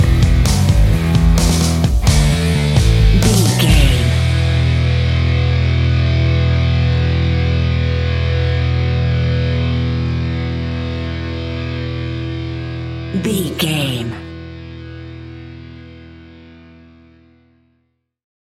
Epic / Action
Fast paced
Aeolian/Minor
hard rock
blues rock
Rock Bass
heavy drums
distorted guitars
hammond organ